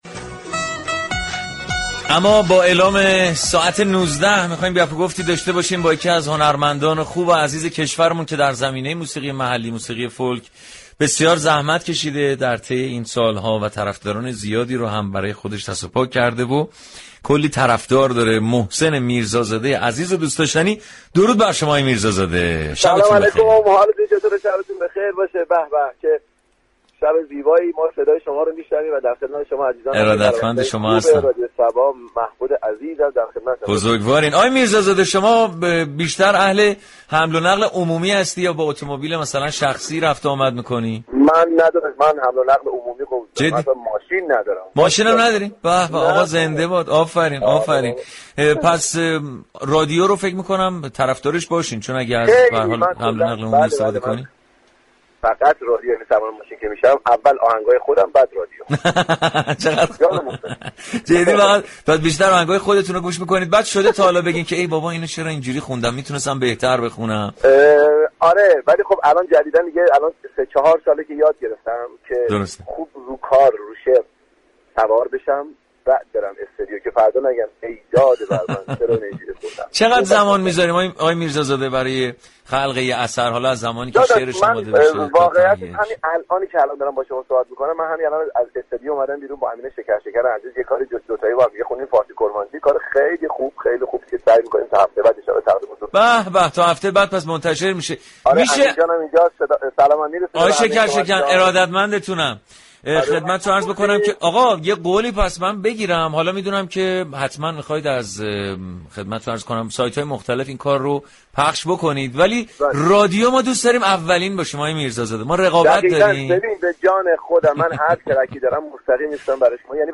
به گزارش روابط عمومی رادیو صبا، برنامه موسیقی محور صباهنگ در هر قسمت میزبان یكی از هنرمندان عرصه موسیقی است و با آنها درخصوص موسیقی گفتگو صمیمی و شادی دارد.